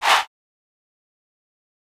get right witcha chant.wav